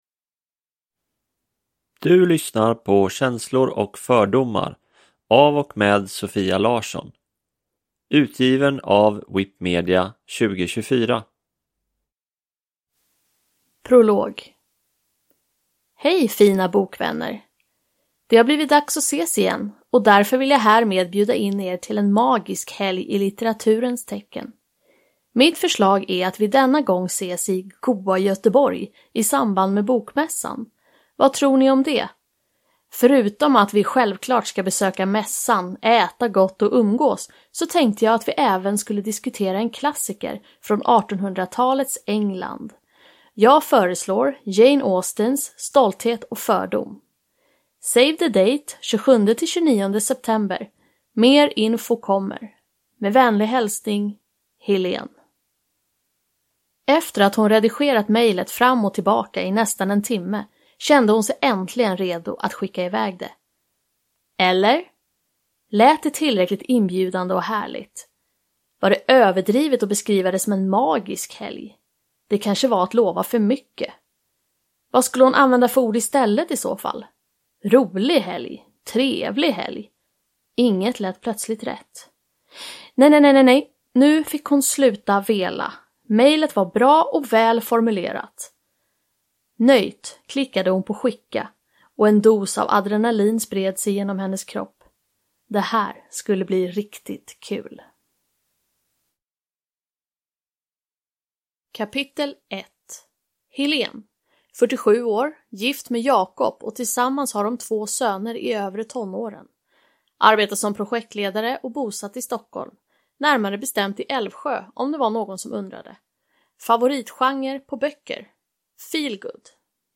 Känslor och fördomar (ljudbok